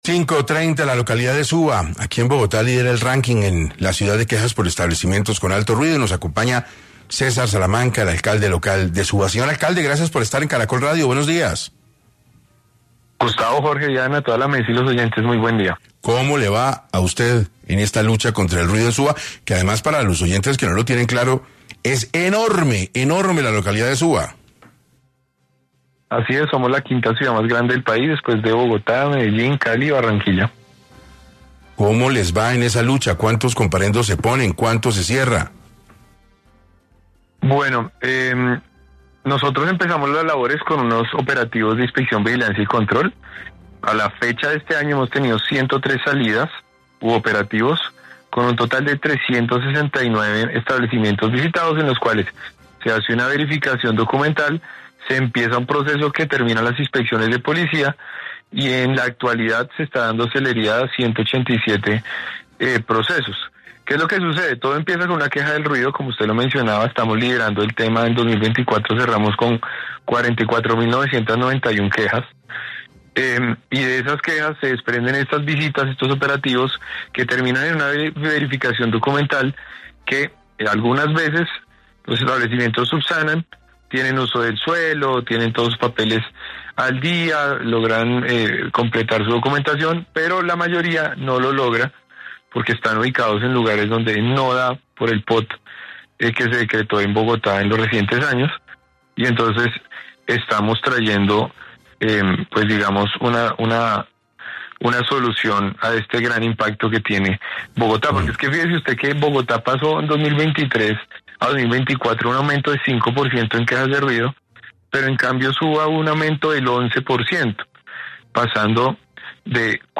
En 6AM de Caracol Radio estuvo César Salamanca, alcalde local de Suba, quien confirmó que durante 2024 se recibieron más de 44.000 quejas ciudadanas en consecuencia del ruido generado en establecimientos de la localidad.
En entrevista con 6AM de Caracol Radio, el alcalde local de Suba, César Salamanca, entregó un balance preocupante sobre la situación de convivencia en esta localidad del norte de Bogotá.